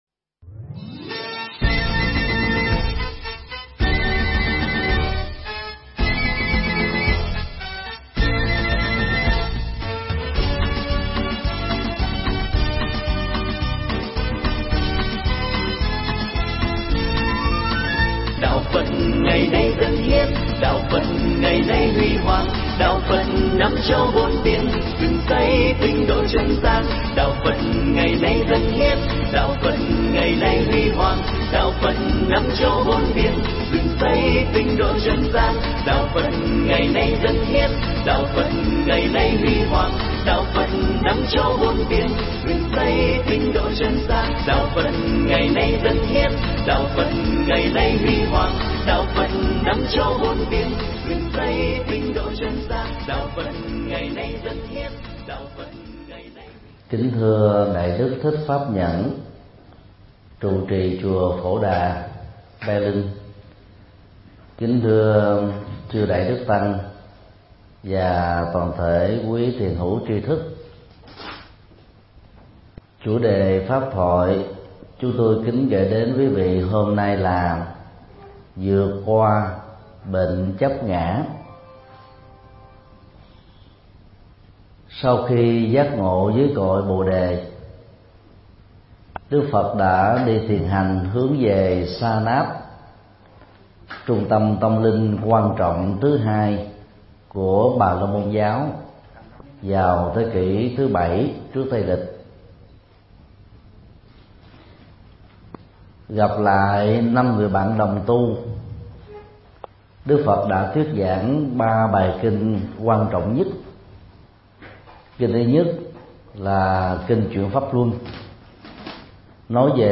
Mp3 Thuyết Pháp Vượt qua bệnh chấp ngã
Giảng tại Chùa Phổ Đà – Berlin, Cộng Hòa Liên Bang Đức